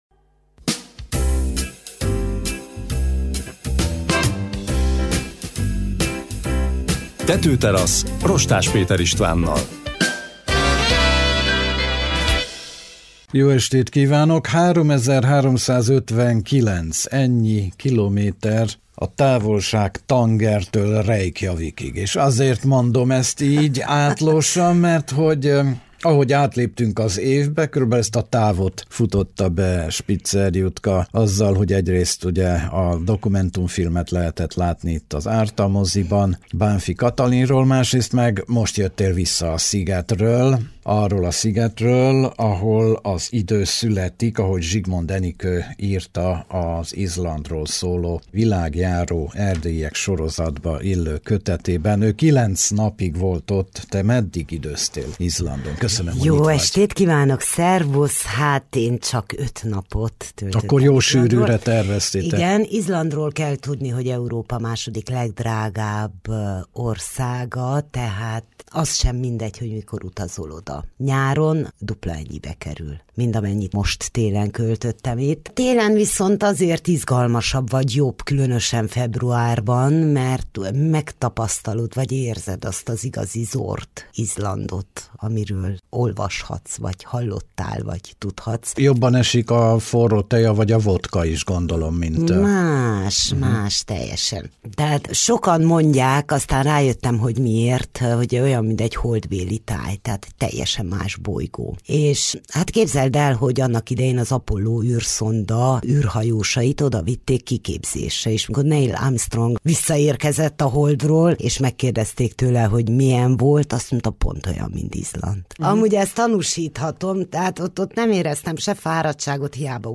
televíziós újságíróval beszélgetünk.